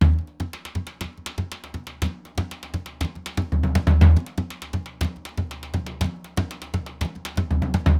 Surdo Merengue 120_1.wav